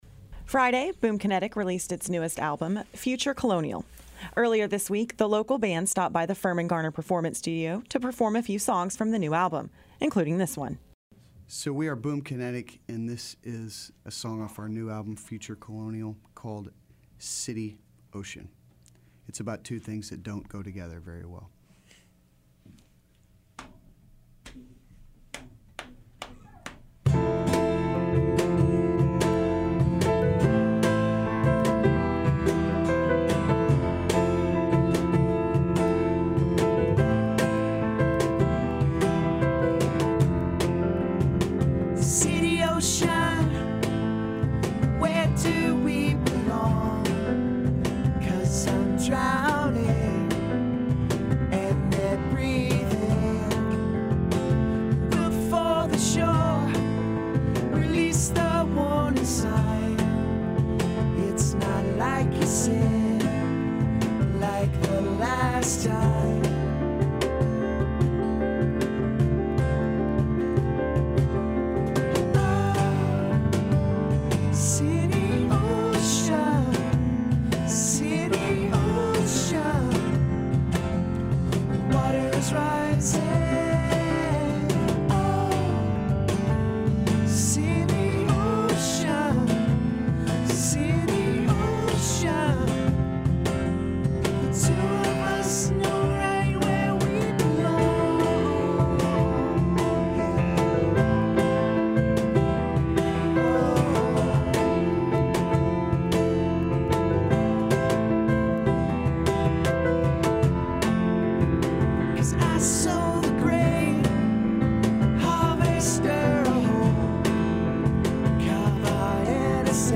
During a recent visit to the studio